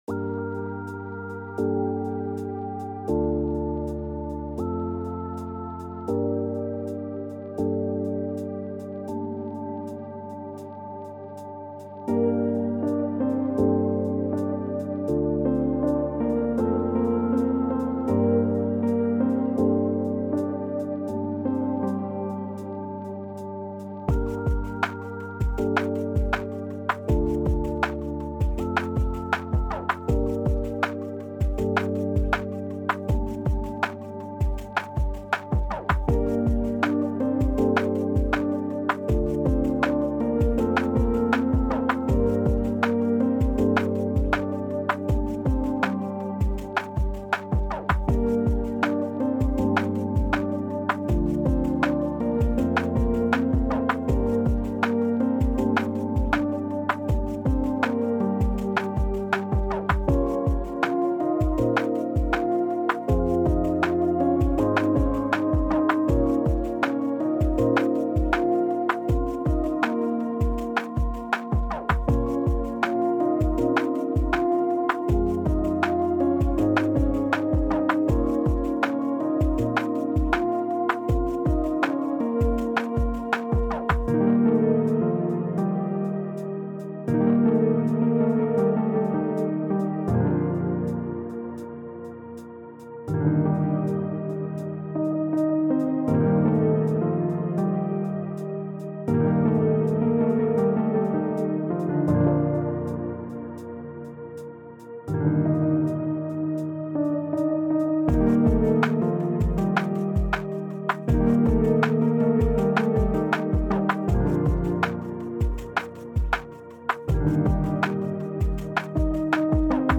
Home > Music > Beats > Smooth > Medium > Laid Back